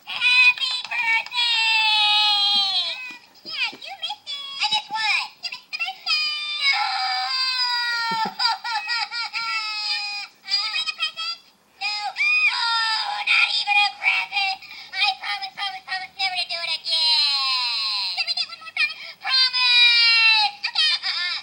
Sorry I Missed Your Birthday is a hoops&yoyo belated birthday greeting card with sound.
Card sound
• Hoops isn't talking in this card.
• This is one of the few cards to have Piddles talking.